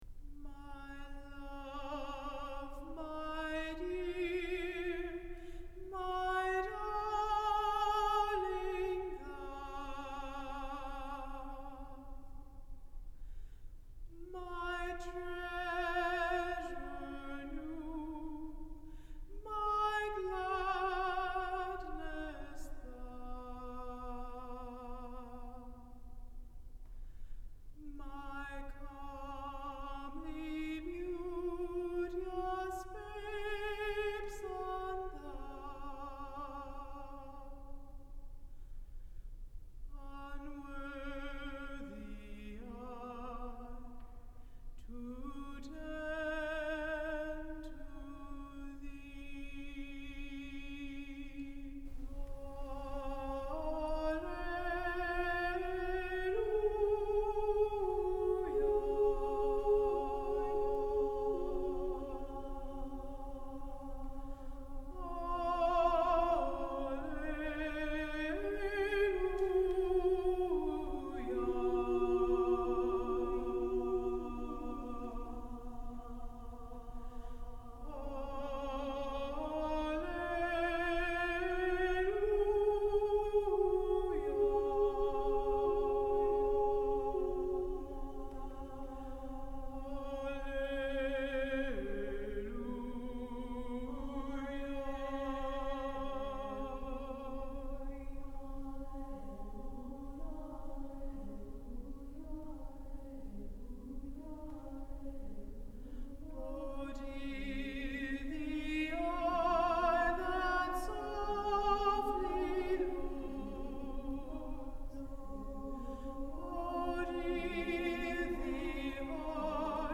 for SATB Chorus and Solo Alto (1988)
She sings alone at the end.